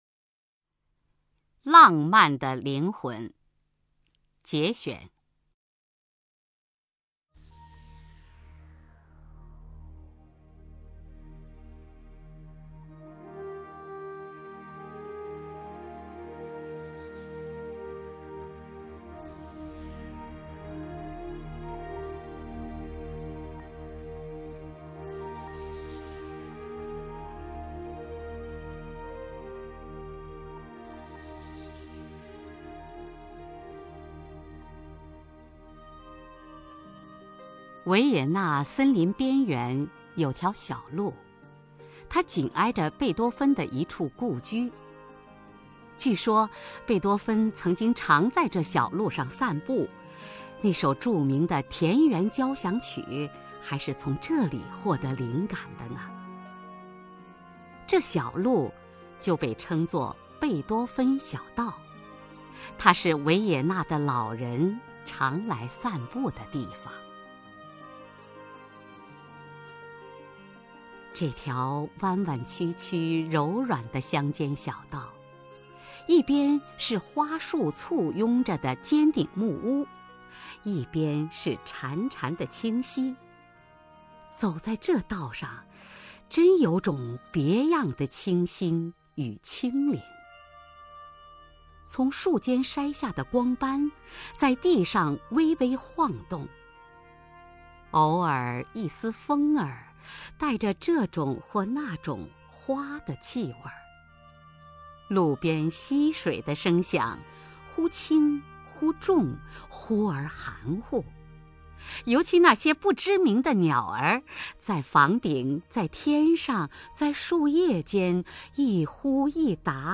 雅坤朗诵：《浪漫的灵魂（节选）》(冯骥才) 冯骥才 名家朗诵欣赏雅坤 语文PLUS